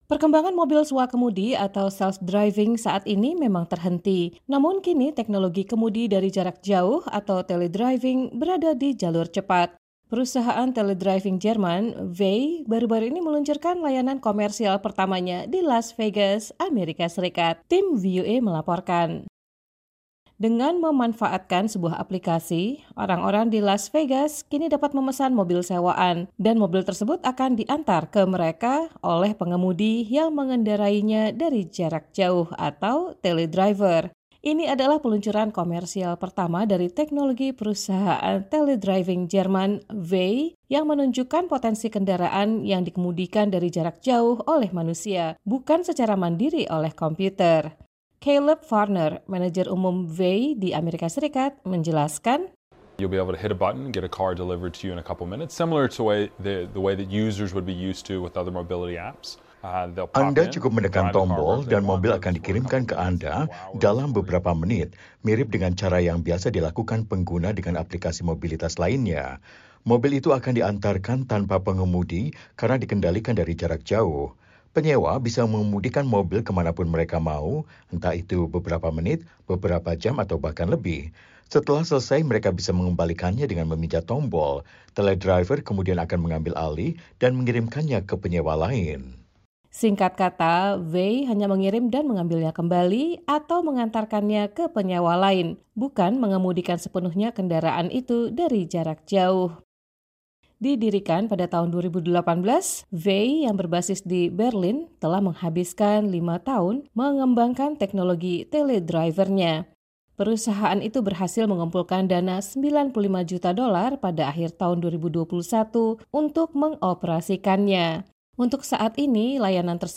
Perusahaan teledriving Jerman, Vay, baru-baru ini meluncurkan layanan komersial pertamanya di Las Vegas, Amerika Serikat. Tim VOA melaporkan.